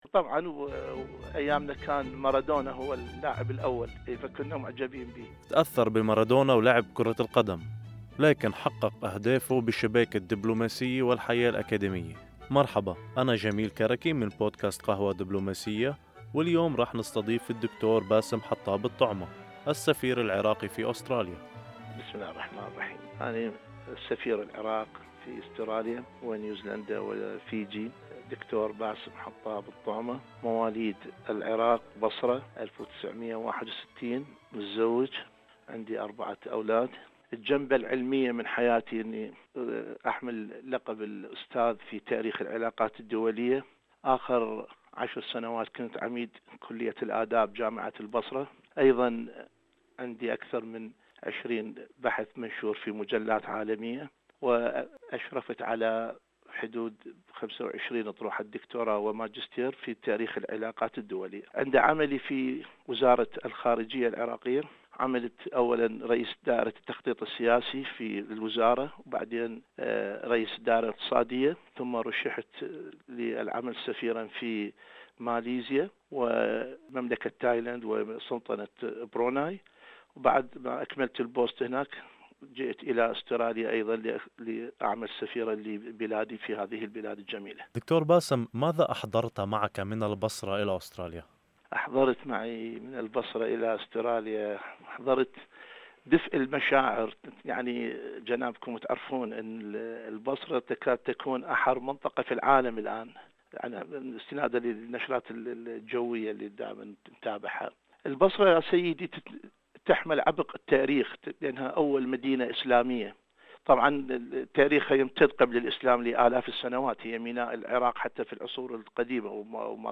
اليوم يستضيف بودكاست قهوة دبلوماسية السفير العراقي في أستراليا ونيوزيلندا وفيجي د.باسم حطاب الطعمة.